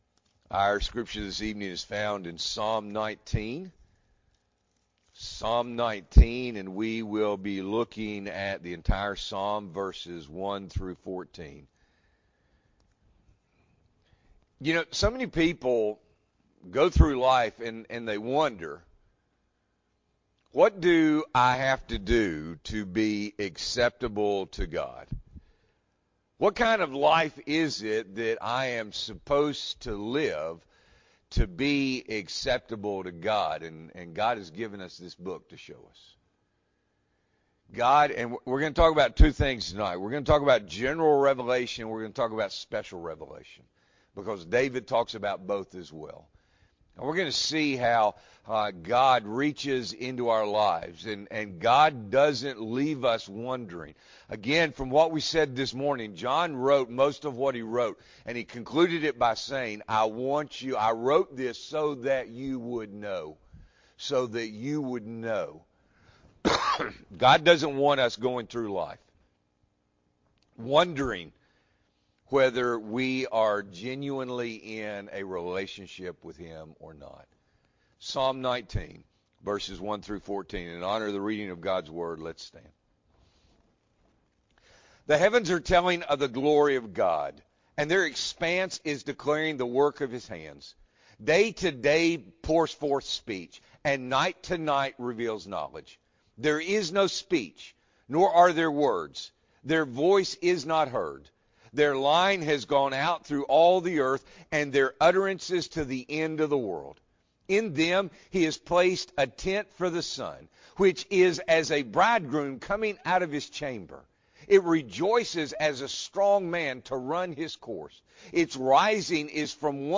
April 23, 2023 – Evening Worship